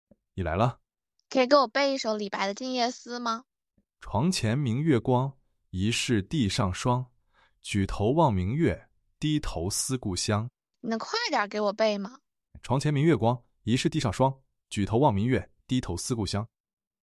比如，让Step-Audio 2 mini变换语调读《静夜思》：